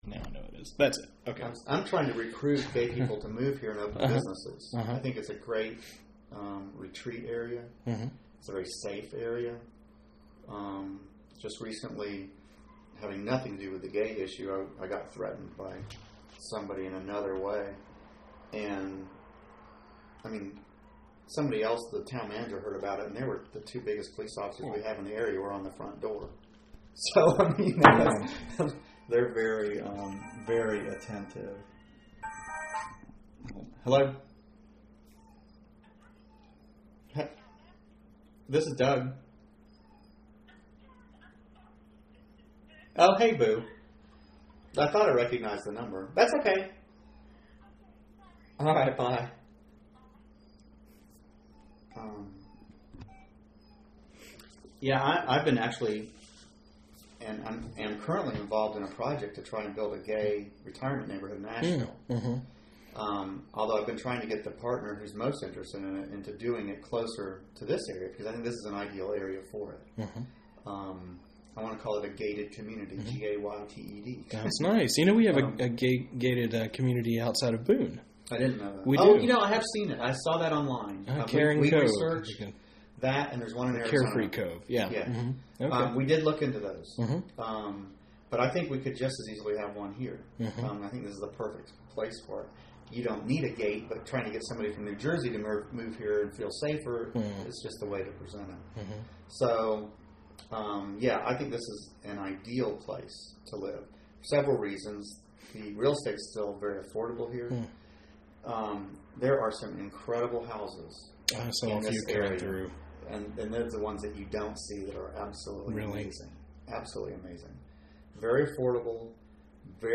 Appalachian Lesbian, Gay, Bisexual, and Transgender Oral History Project